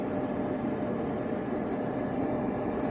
ventilation.ogg